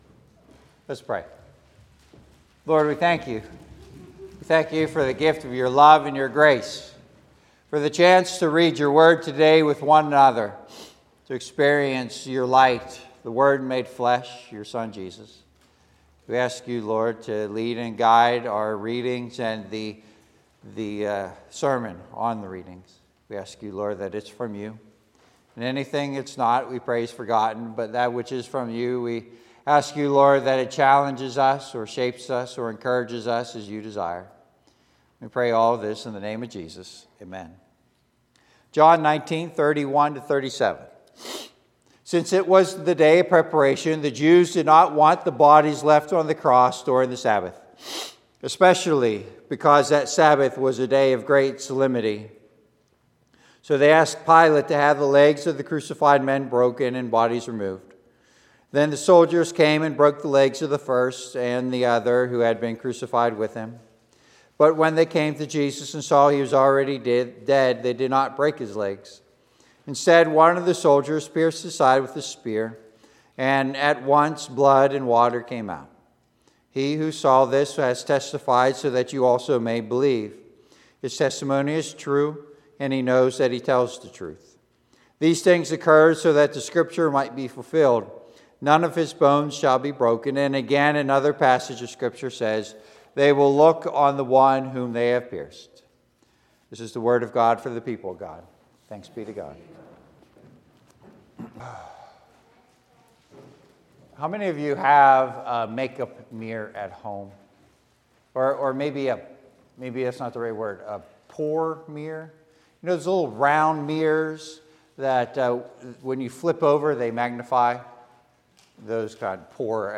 Bible Text: John 19:31-37 | Preacher